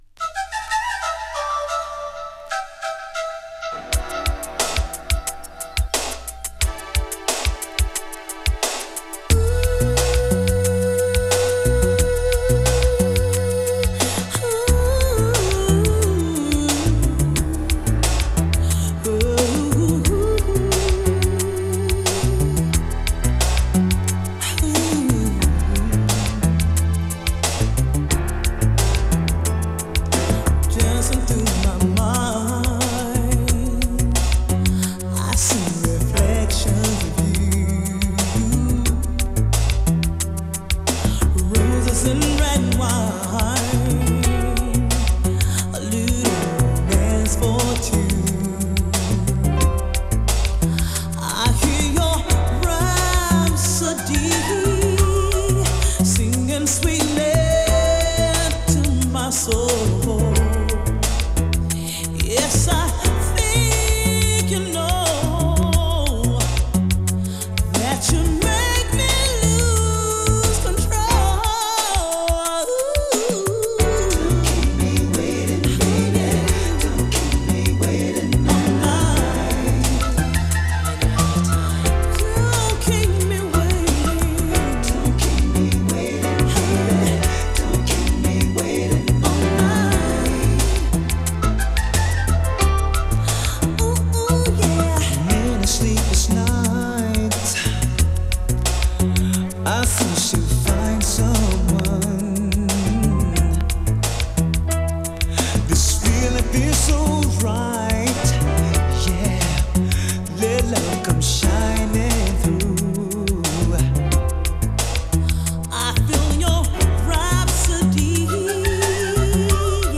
2. > SOUL/FUNK
メロウ、フリーソウルな名曲を多数収録！